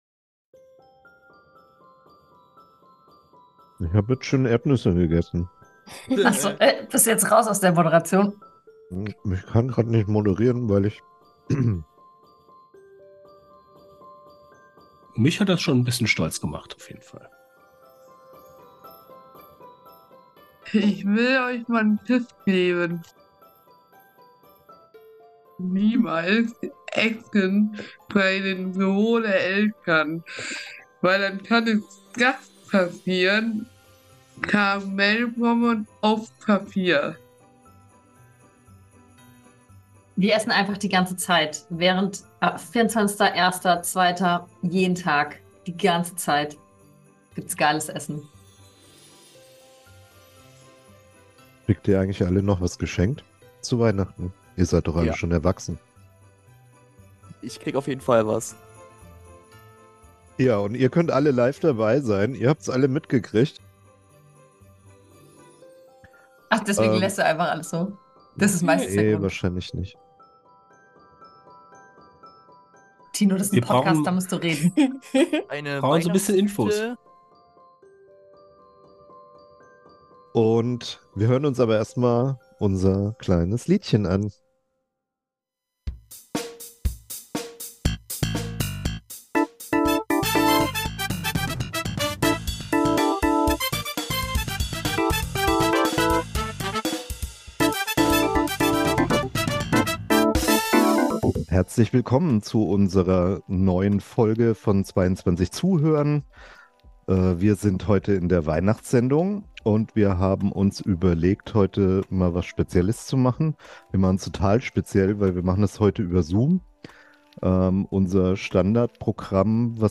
Wir haben uns mit 8 Leuten zusammengeschaltet und beschenken uns in einer geselligen Runde. Außerdem: Hausmitteilungen: Neue Projektmitglieder, Wir sind Dieter Baacke Preisträger, Musik und Gesang.